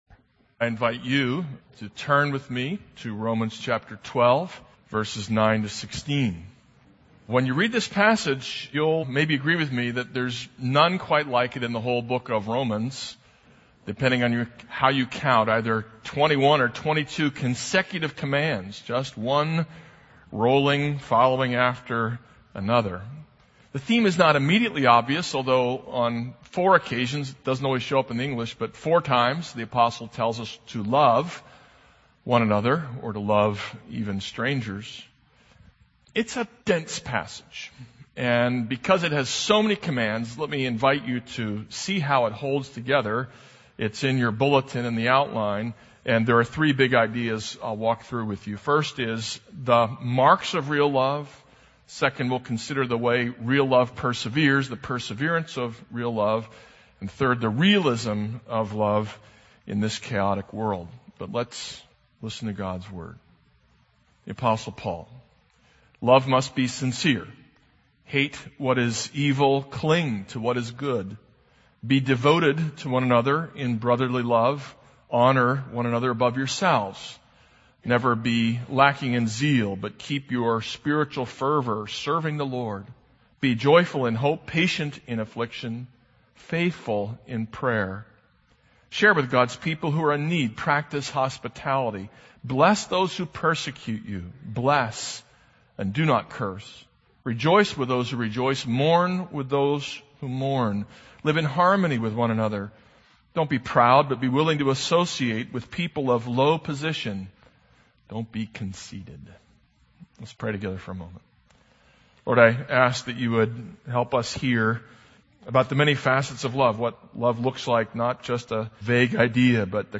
This is a sermon on Romans 12:9-16.